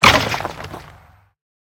25w18a / assets / minecraft / sounds / mob / wither_skeleton / death1.ogg
death1.ogg